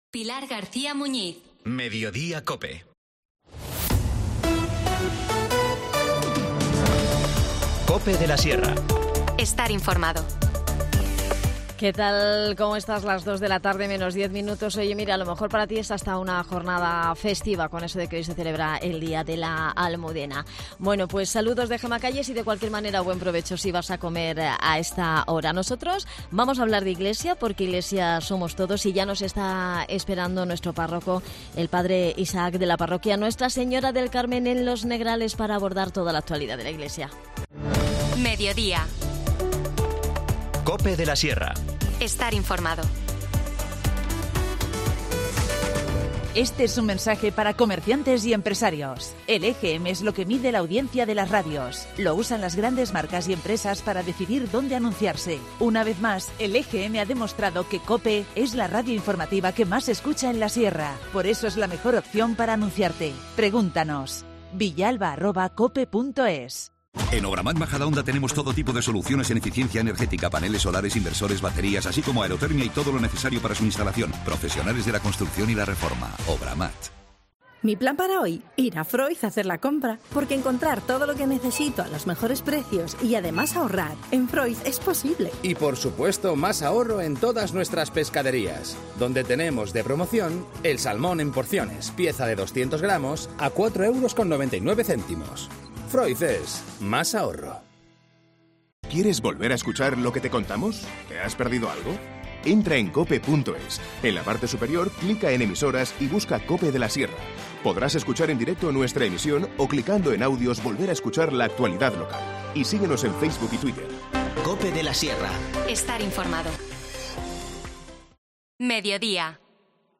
Conversamos sobre esta jornada